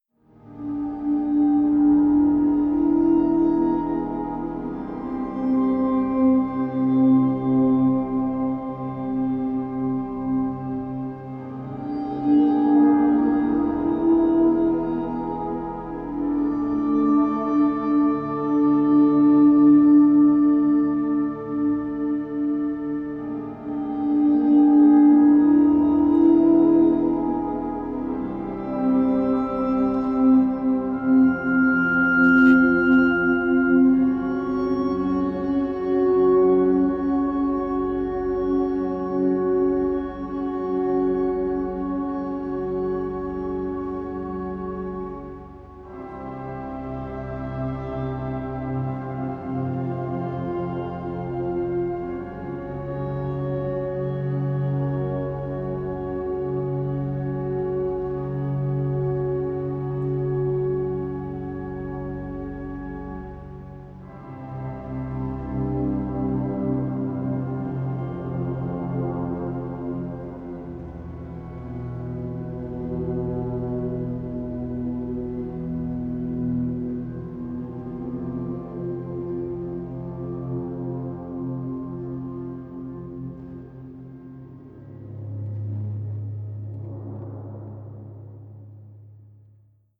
Stücke für Orgel und Stahlcelli
Das Programm spielt damit, Klänge entstehen zu lassen, die für den Zuhörer nicht mehr klar der Orgel oder dem STAHLQUARTETT zuzuordnen sind.